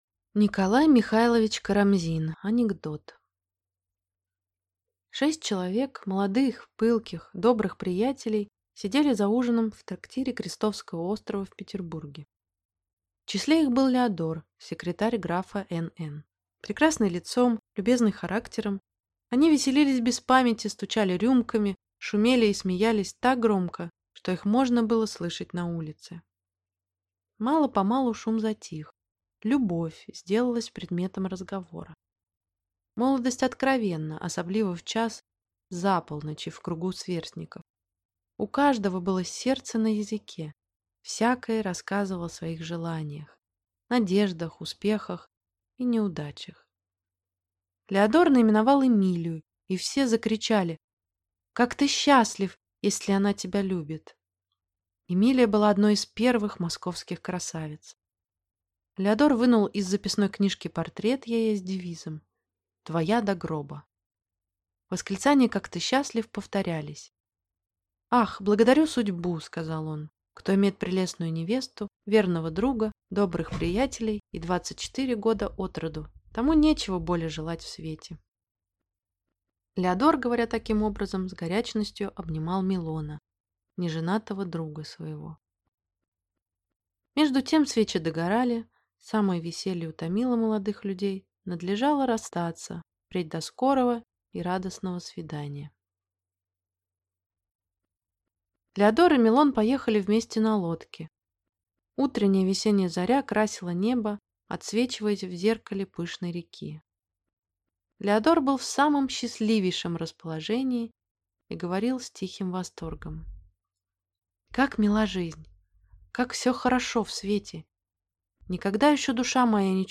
Аудиокнига Анекдот | Библиотека аудиокниг